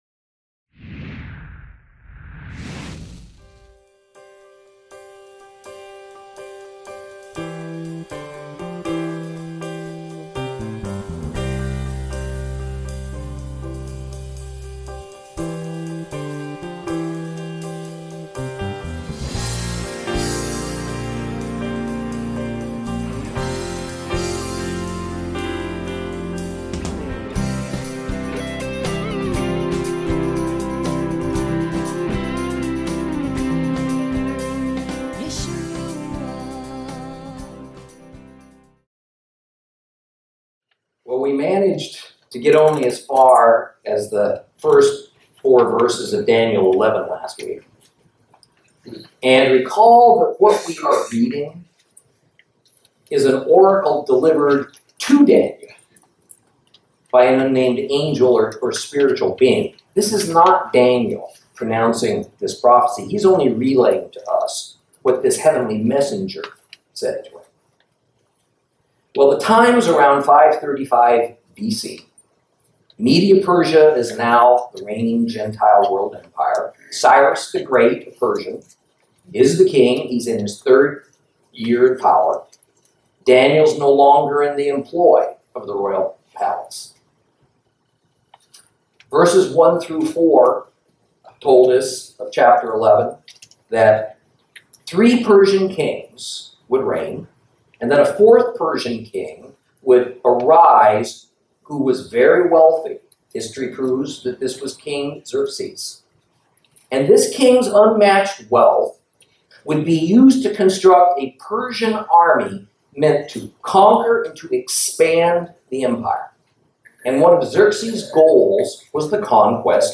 Lesson 31 Ch11 - Torah Class